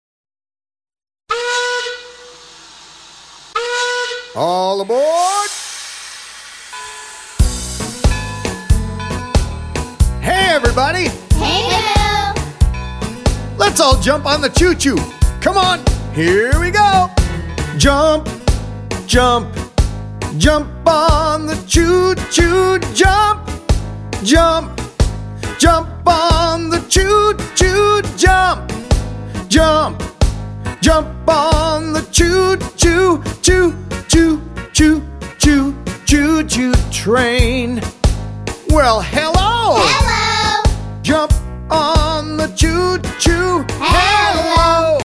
-23 simple, catchy songs
-Kids and adults singing together and taking verbal turns